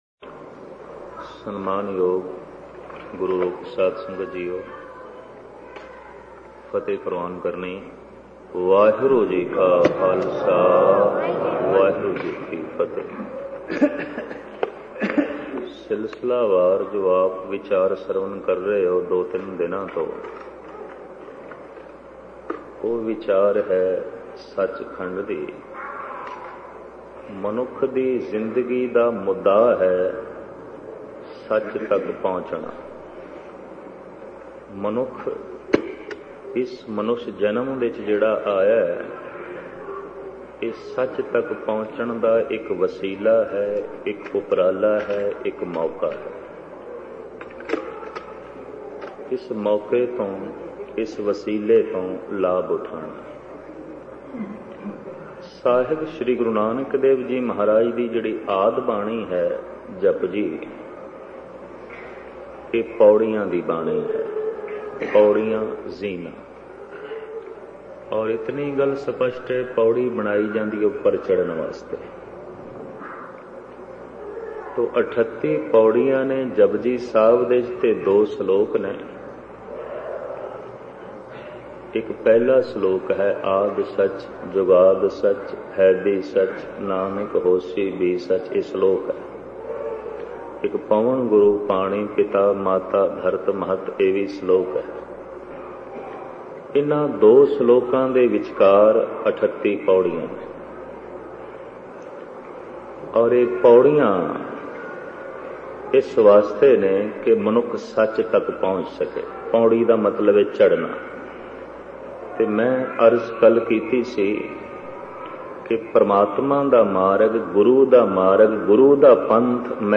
Sach Khand Genre: Gurmat Vichar